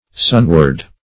sunward - definition of sunward - synonyms, pronunciation, spelling from Free Dictionary Search Result for " sunward" : The Collaborative International Dictionary of English v.0.48: Sunward \Sun"ward\, adv. Toward the sun.